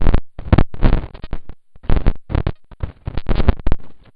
garble3.wav